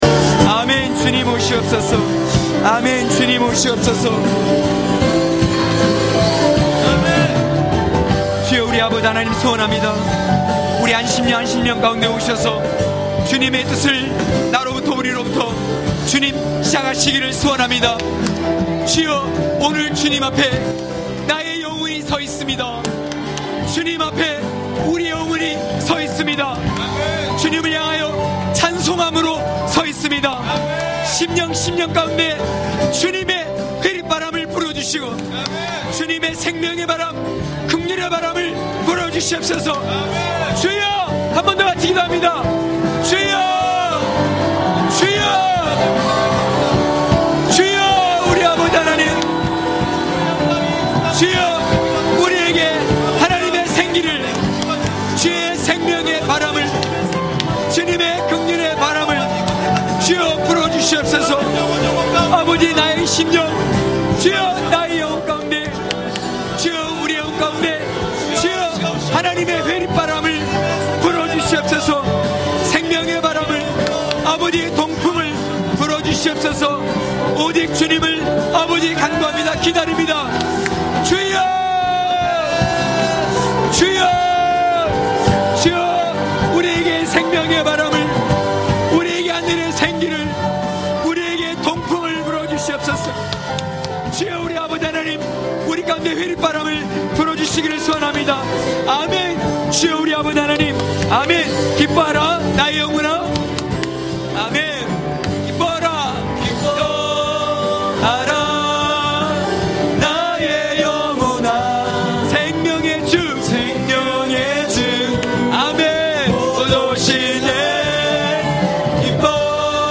강해설교 - 23.성벽 위에 선 자들(느13장26~31절).mp3